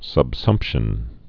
(səb-sŭmpshən)